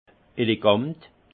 Ville Prononciation 68 Munster